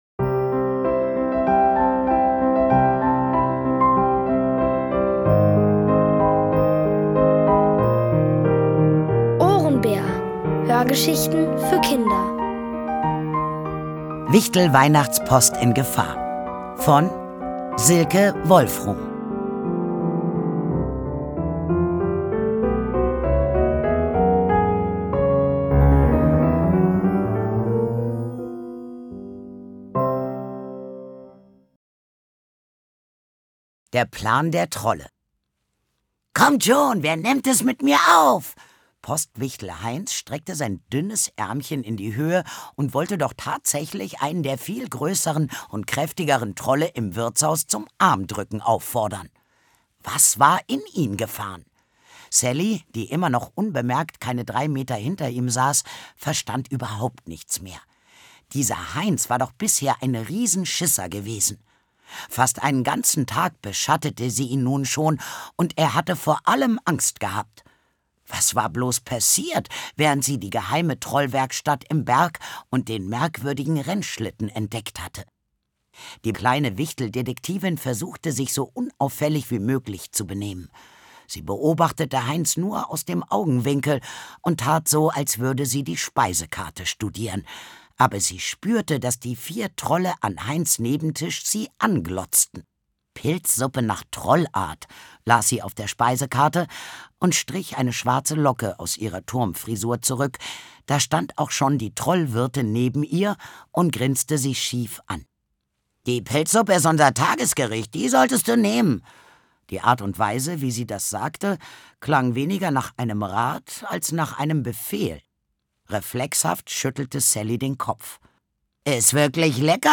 Von Autoren extra für die Reihe geschrieben und von bekannten Schauspielern gelesen.
Es liest: Sandra Schwittau.